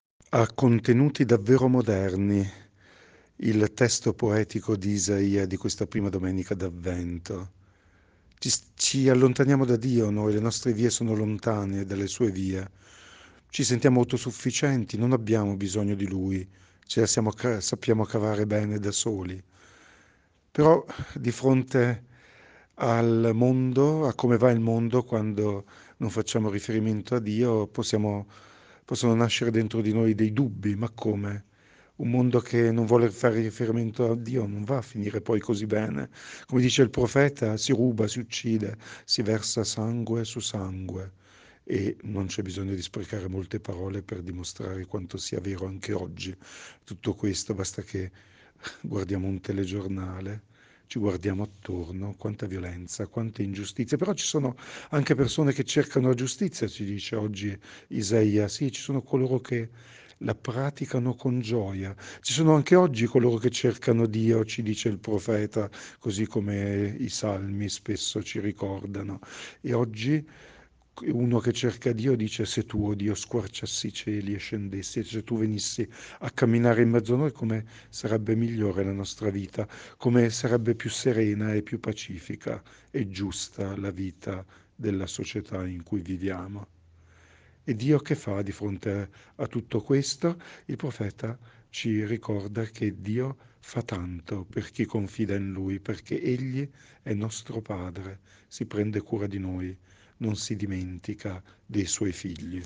Meditazione Domenica 3 Dicembre 2023 – Parrocchia di San Giuseppe Rovereto